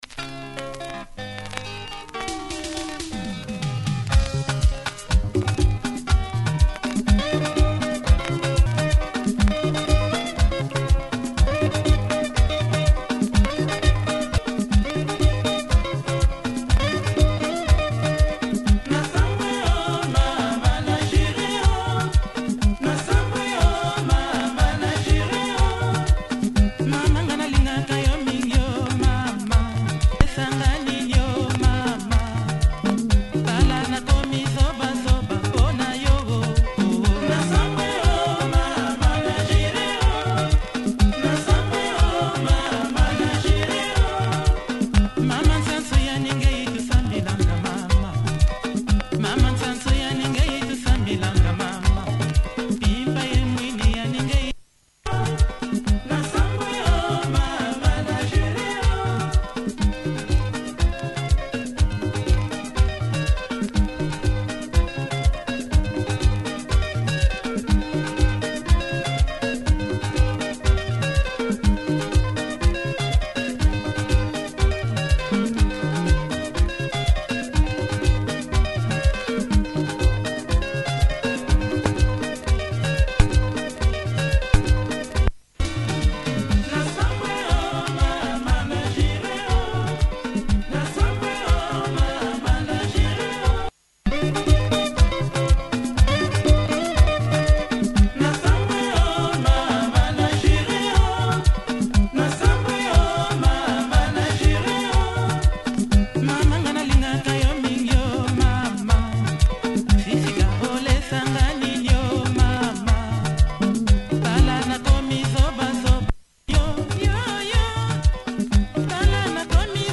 club vibe!